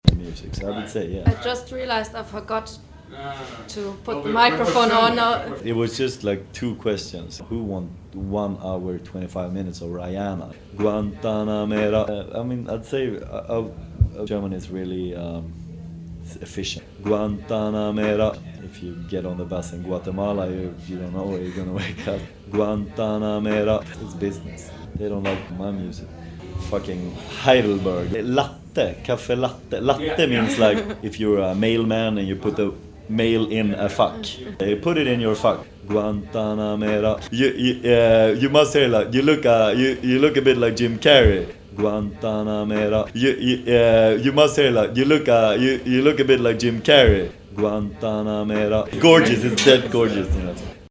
zwar länger her, aber nichtsdestorotz erwähnenswert: mein mittlerweile schon legendäres interview mit moneybrother. seinen echten namen habe ich wieder vergessen, also kann der nicht so wichtig sein.